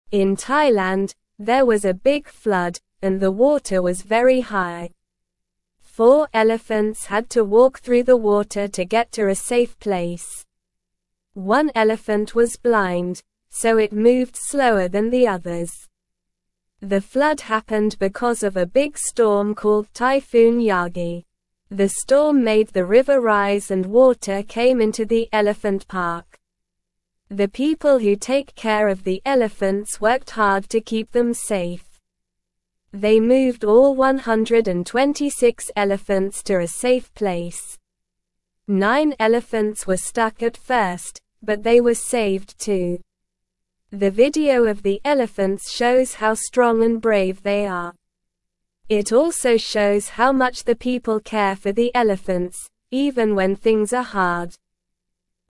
Slow
English-Newsroom-Beginner-SLOW-Reading-Elephants-Brave-Flood-in-Thailand-People-Keep-Safe.mp3